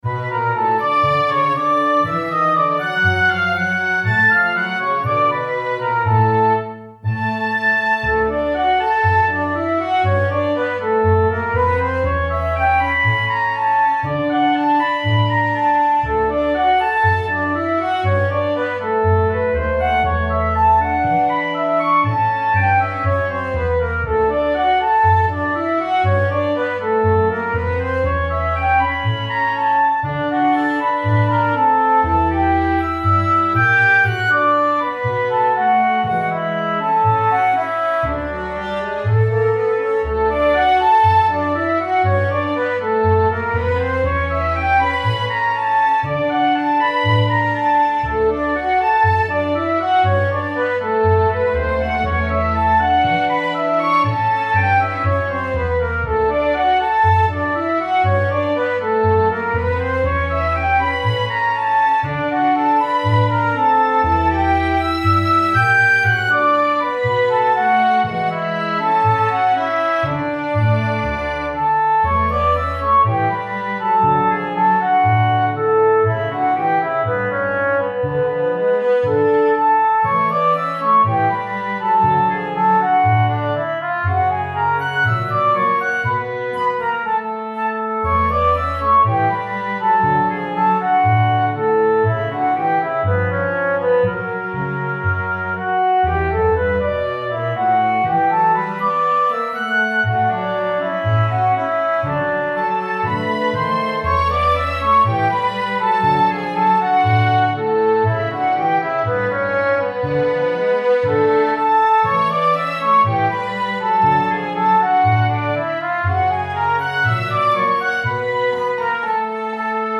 Instrumentation: misc strings, C, Bb, Eb,
gtr chords
A flexible arrangement for wind, strings etc.
Minimum forces 2 x melody + bass.